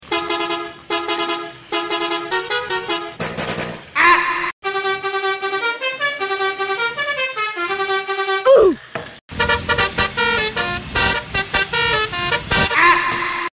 Mainan Anak, kereta Api dan sound effects free download
Mainan Anak, kereta Api dan mobil-mobilan Anak